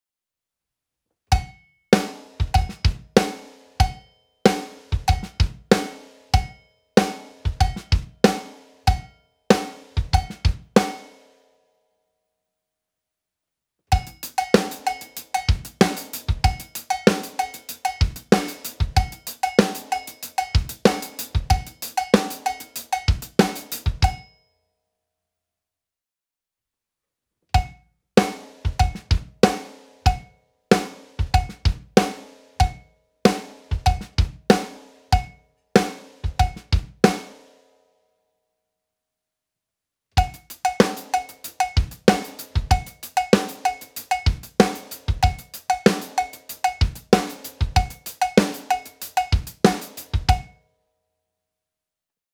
MEINL Percussion Chrome & Steel Series Low Cha Cha Cowbell - 4 1/2" (STB45L)
The pitches of these MEINL Percussion Cowbells form a diatonic scale which makes it easy to use multiple bell combinations in any musical setting.